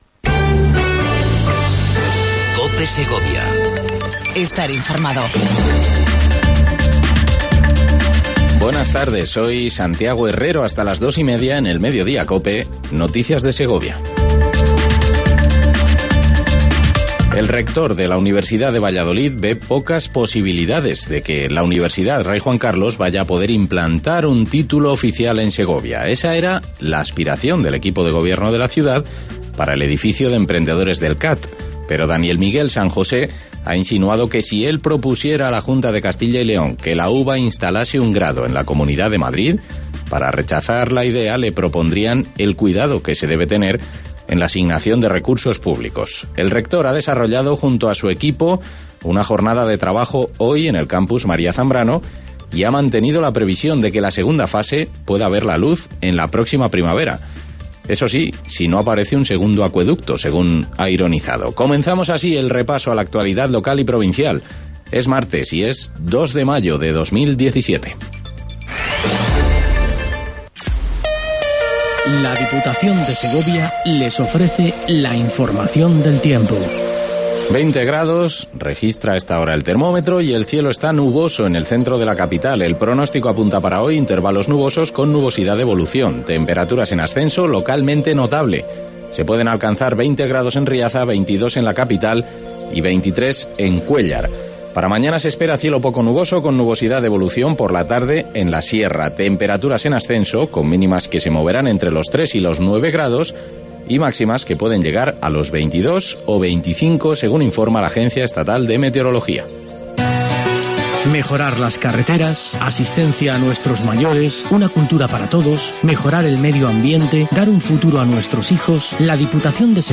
INFORMATIVO MEDIODIA COPE EN SEGOVIA 02 05 17